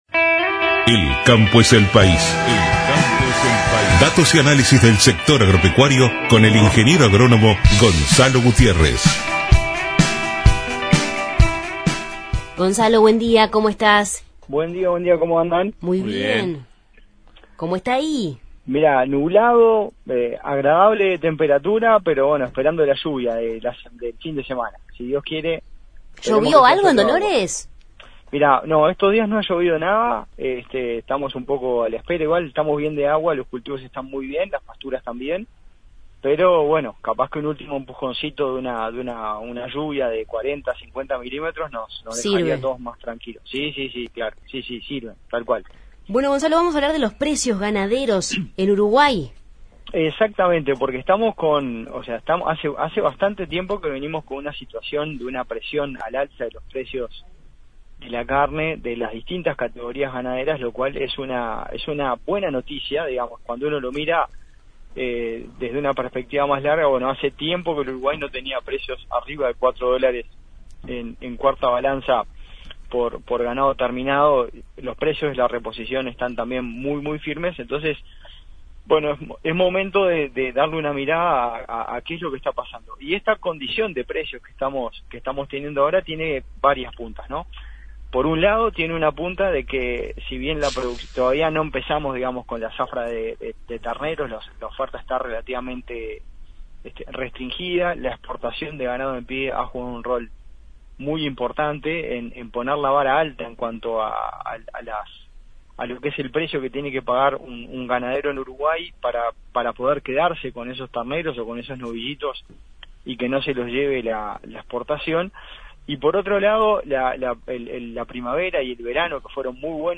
Columna